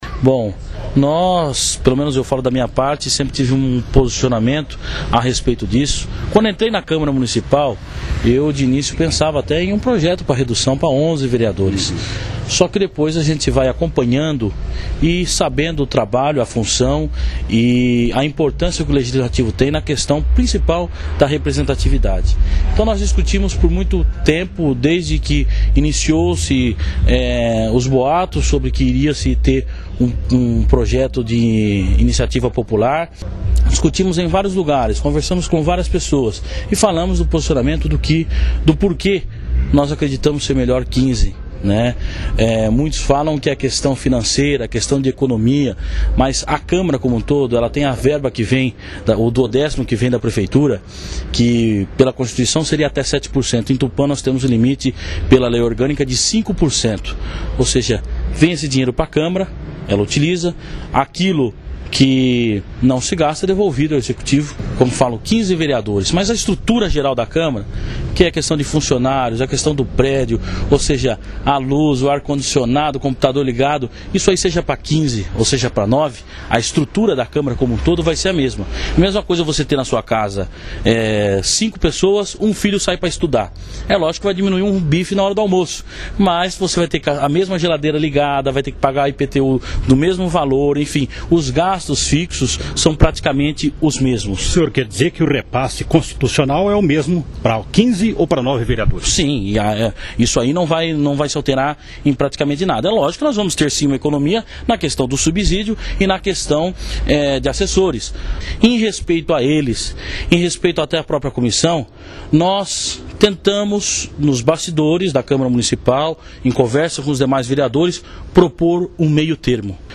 Os vereadores Caio Aoqui (PSDB) e José Ricardo Raymundo (PV), explicaram em entrevista à Rádio Cidade FM (91,5) o motivo que os levaram a retirar as assinaturas da emenda apresentada pelo vereador Danilo Aguillar Filho (PMDB).